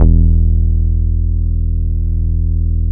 ARA PHAT BAS.wav